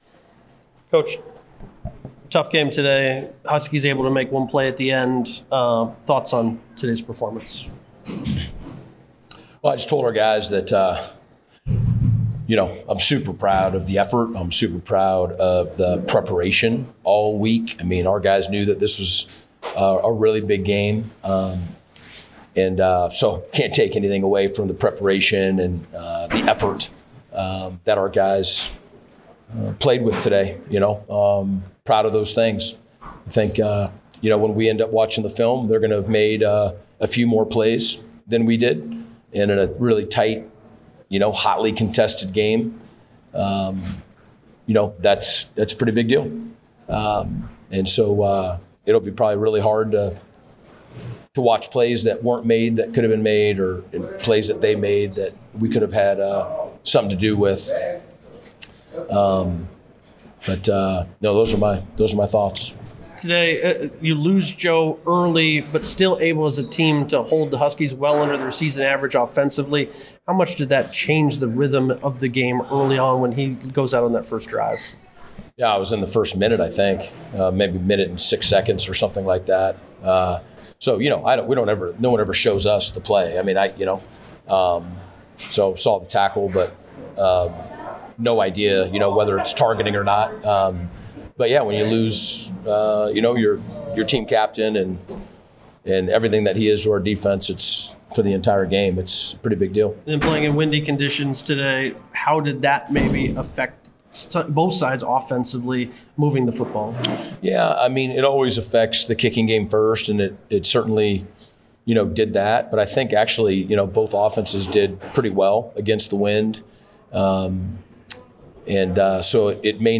Postgame Audio | Highlights | Photo Gallery